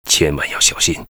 文件 文件历史 文件用途 全域文件用途 Bk2_fw_01.ogg （Ogg Vorbis声音文件，长度1.1秒，124 kbps，文件大小：17 KB） 源地址:游戏语音 文件历史 点击某个日期/时间查看对应时刻的文件。